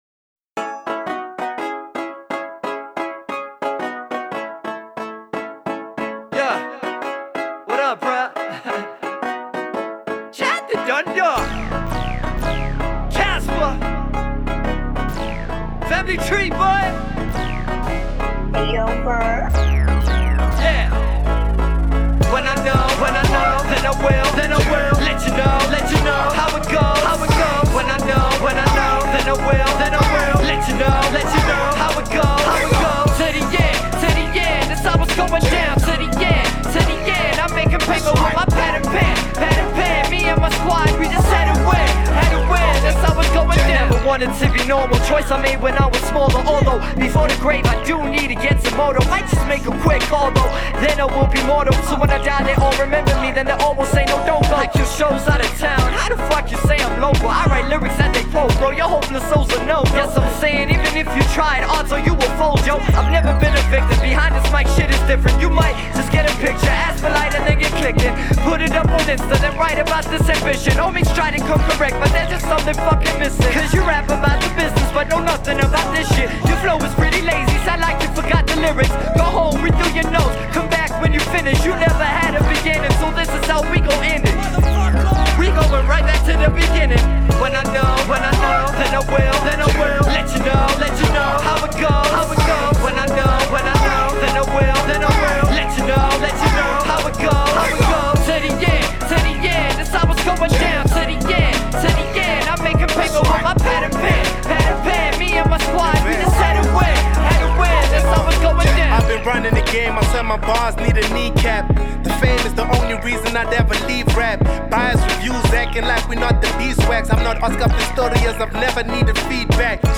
Hip-Hop song